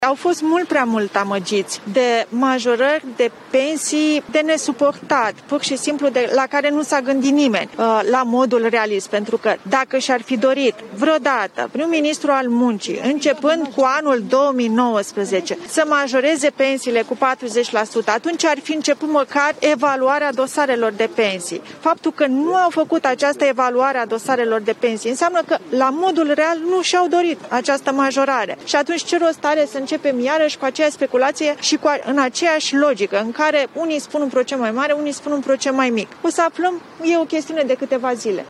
Peste câteva zile vom afla cu cât cresc pensiile, spune ministrul Muncii, Raluca Turcan.
02feb-14-Turcan-despre-majorare-pensii.mp3